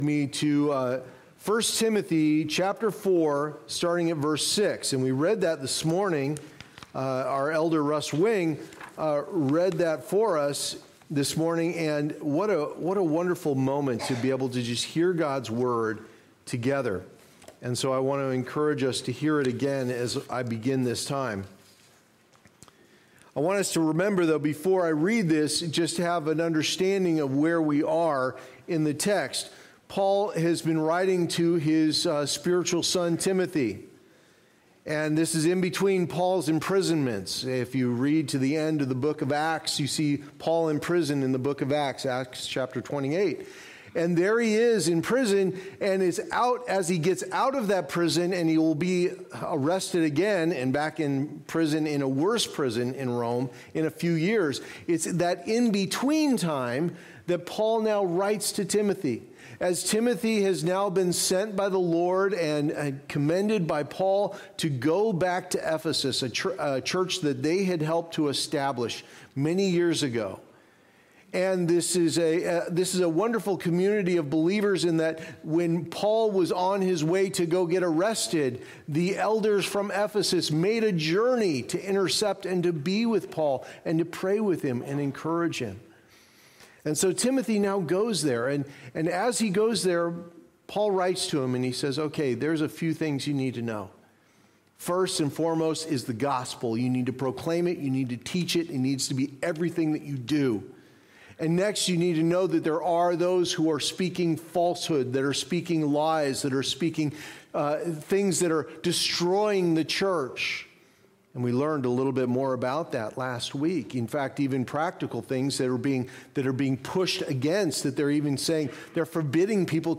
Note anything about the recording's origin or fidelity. Passage: I Timothy 4:6-10 Services: Sunday Morning Service Download Files Notes Previous Next